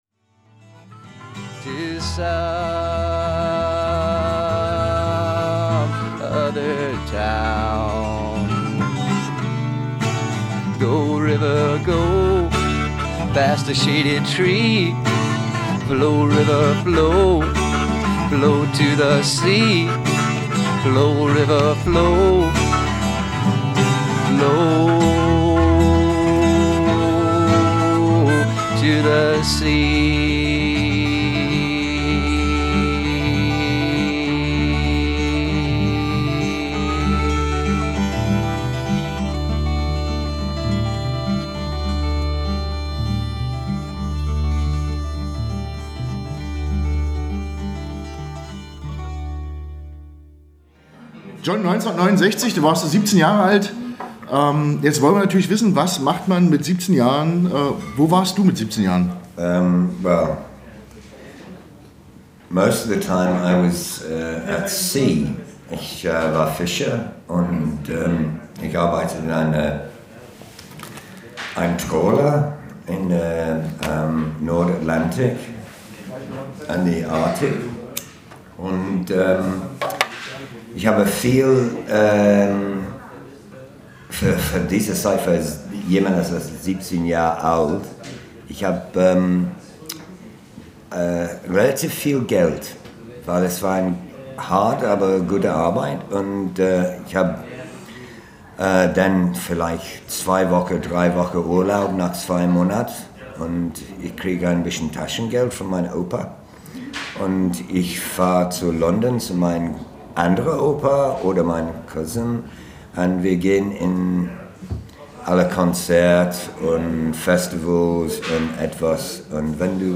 Ausschnitt aus dem Talk